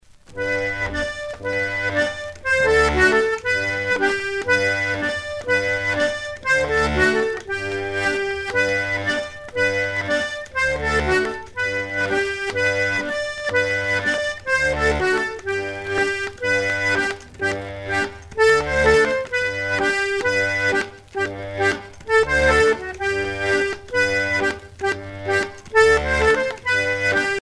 danse : polka piquée
Pièce musicale éditée